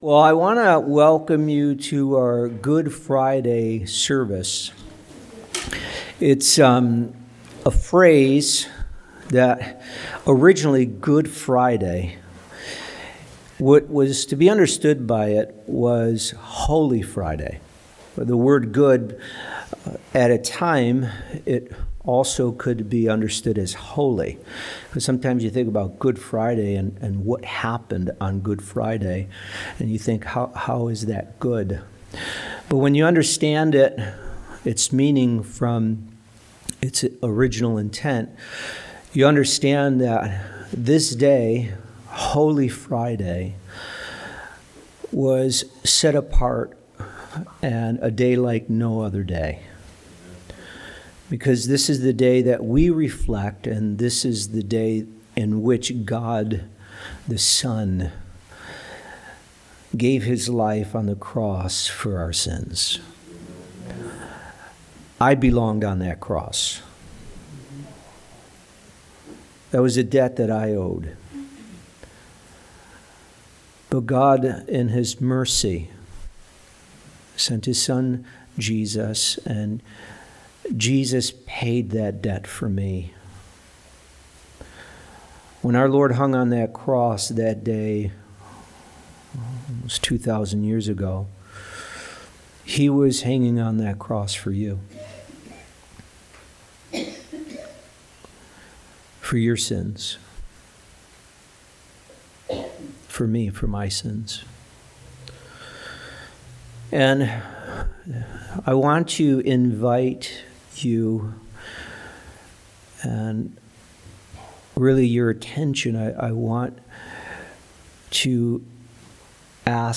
Holiday Service